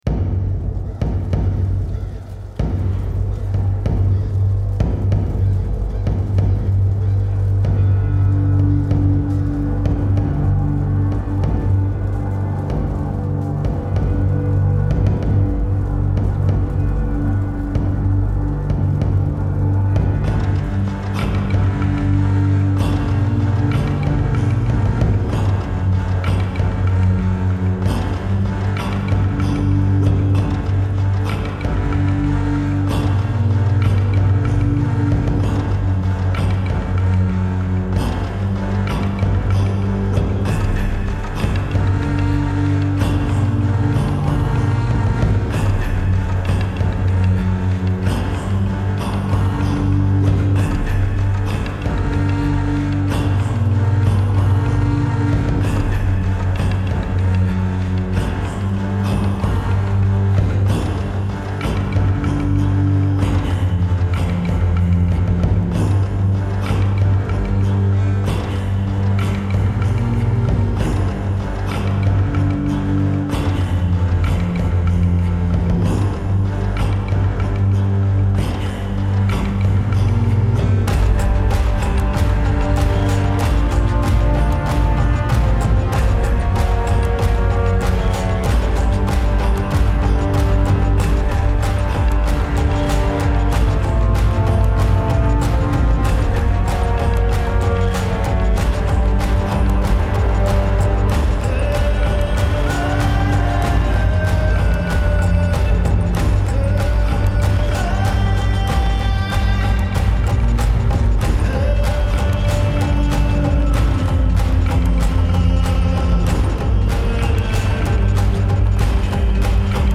Viking Background Music